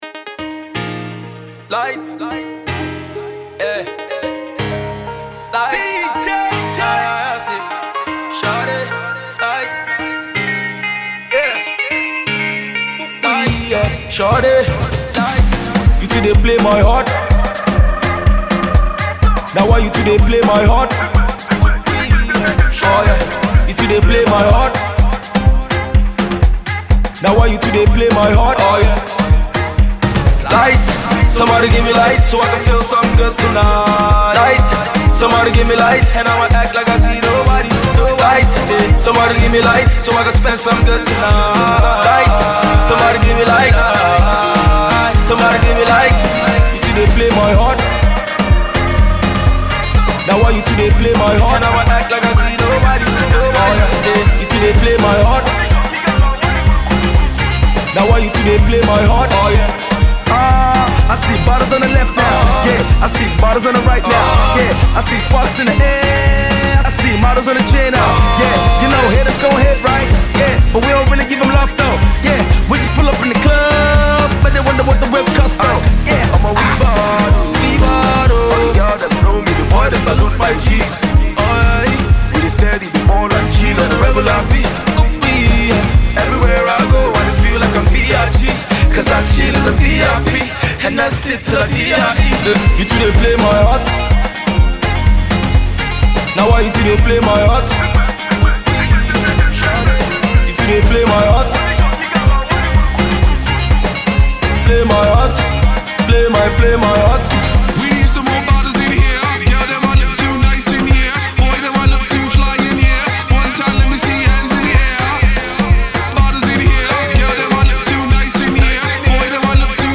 smashing club rave
club anthem